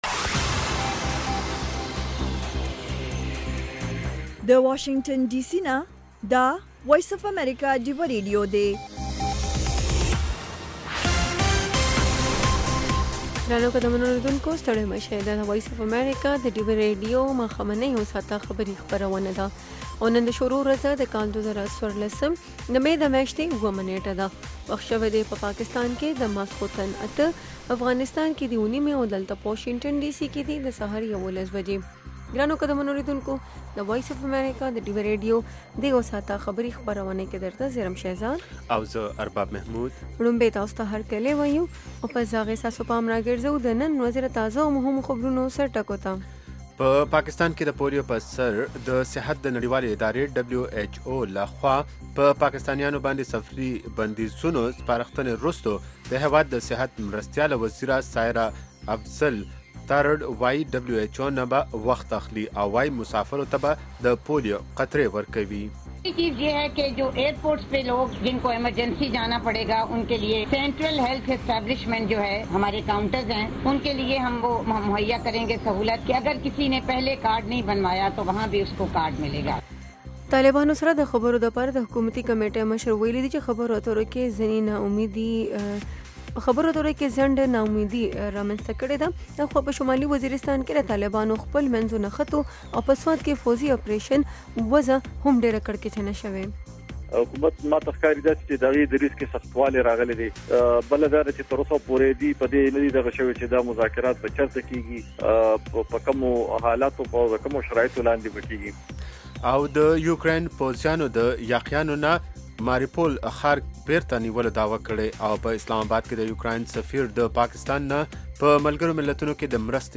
خبرونه - 1500